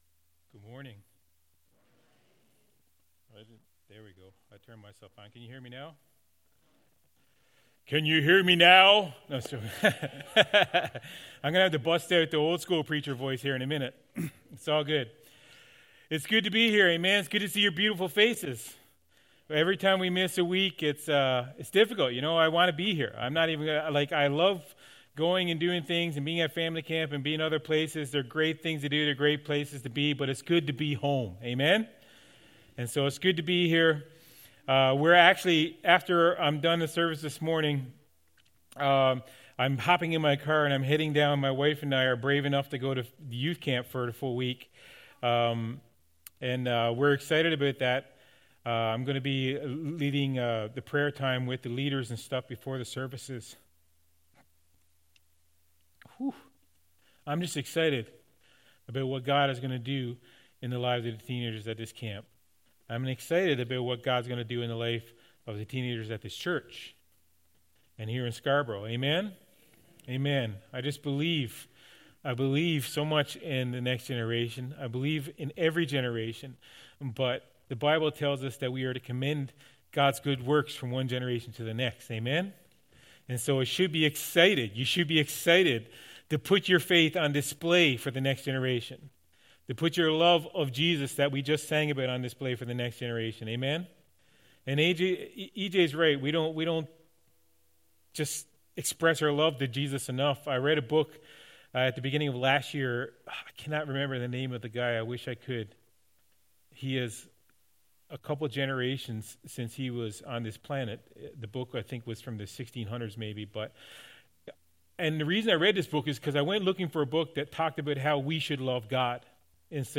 Sermons | Warden Full Gospel Assembly